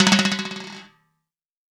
Index of /90_sSampleCDs/AKAI S6000 CD-ROM - Volume 5/Cuba2/TIMBALES_2